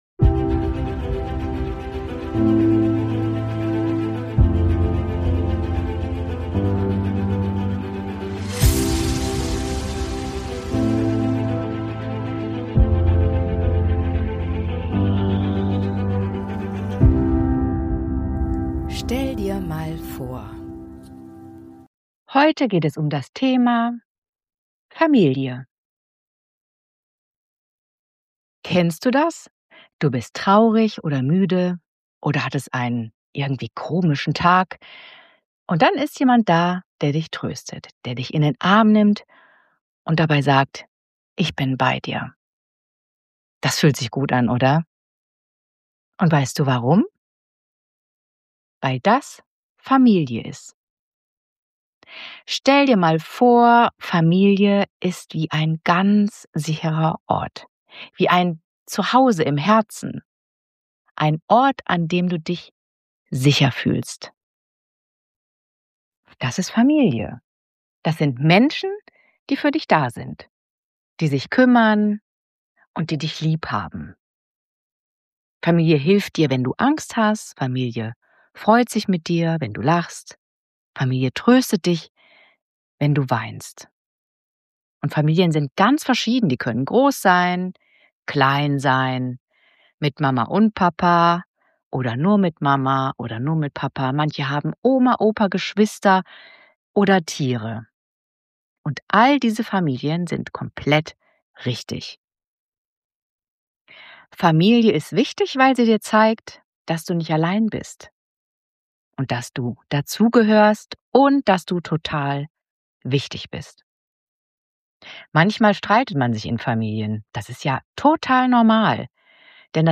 Eine ruhige Fantasiereise und ein liebevoller Abschluss stärken